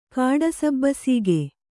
♪ kāḍasabbasīge